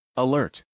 Alert Phrase Sound.wav